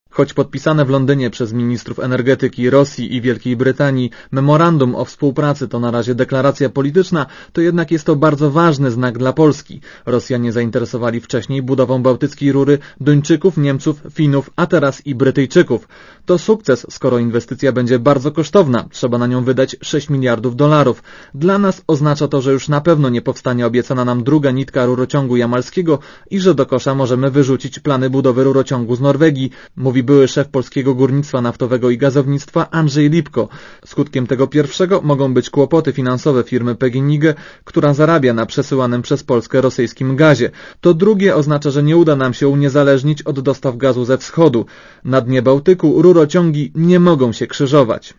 Posluchaj relacji reportera Radia Zet (187 KB)